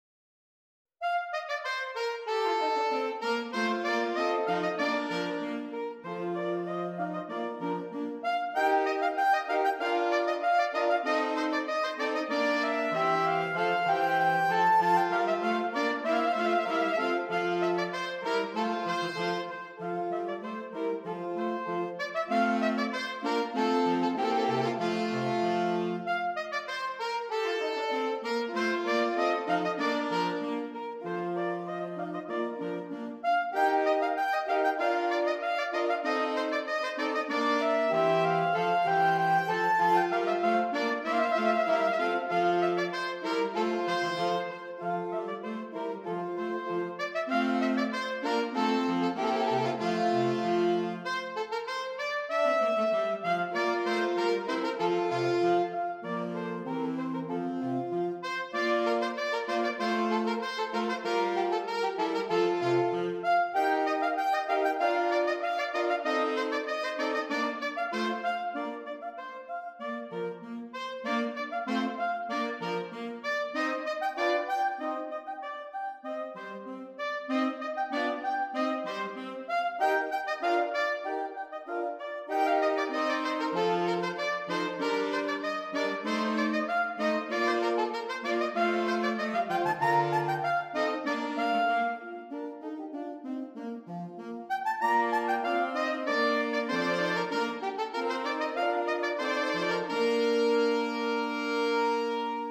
Saxophone Quartet (SAAT)